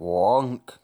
002_long_low.fwonk.wav